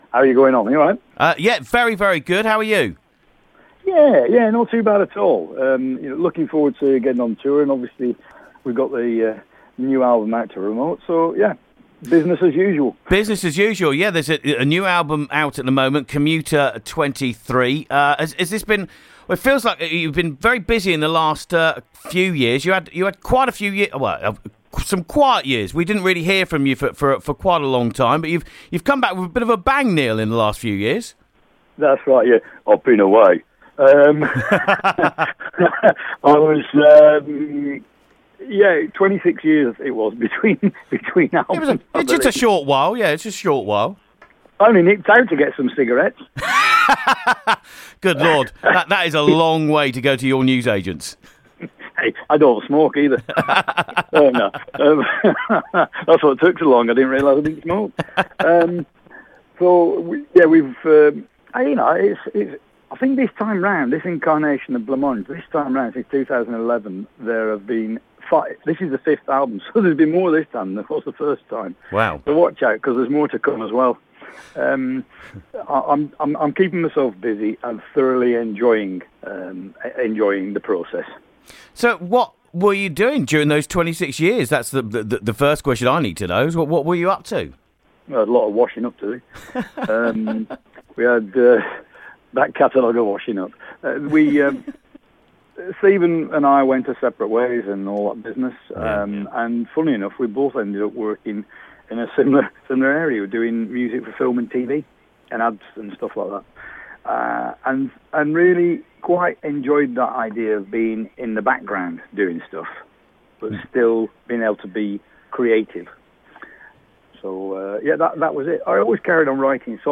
Neil Arthur from Blancmange on Radio Yorkshire